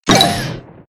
Mole_Stomp.ogg